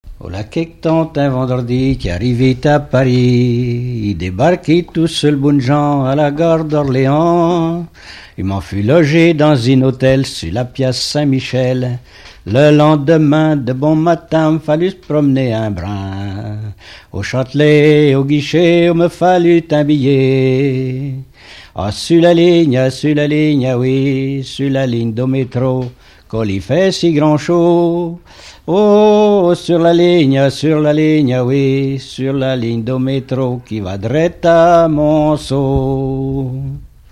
Lettrées patoisantes
Pièce musicale inédite